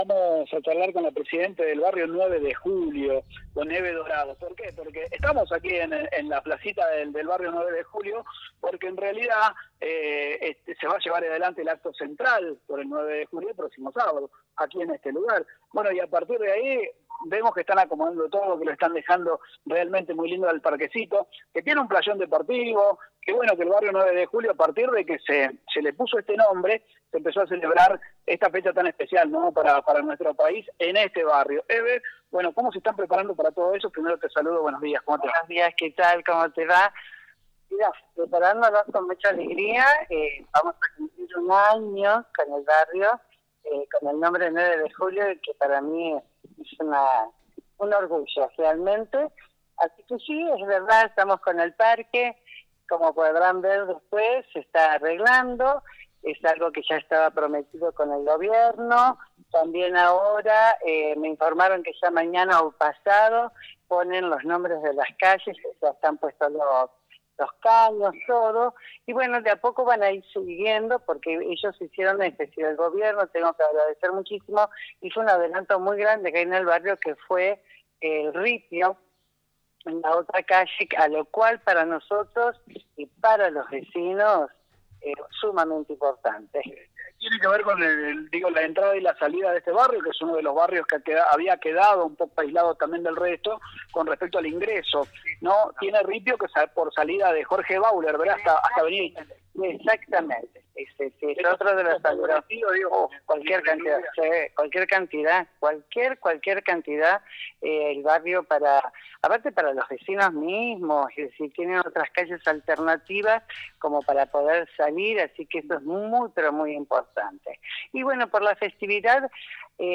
Esta mañana realizamos un móvil en vivo desde la plaza central de Barrio 9 de Julio para conocer las actividades que se realizarán con motivo de conmemorarse el próximo sábado el día de la Independencia.